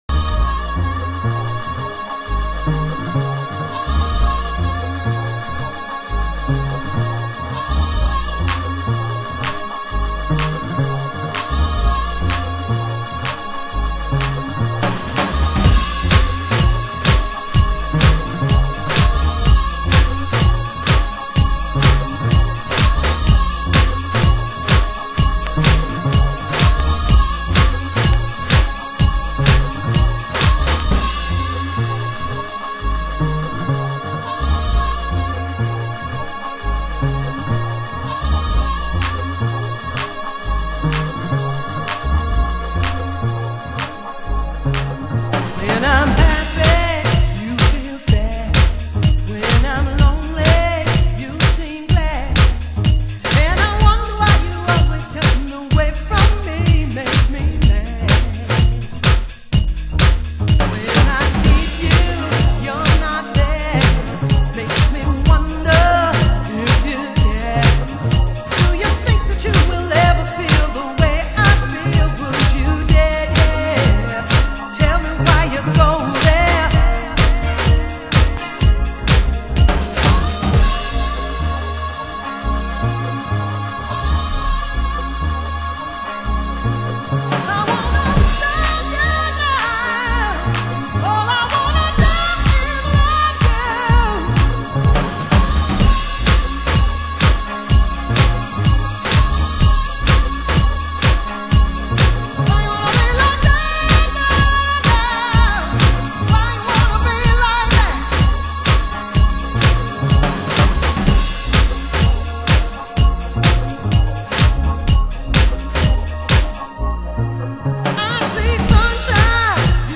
(Vocal) 　 B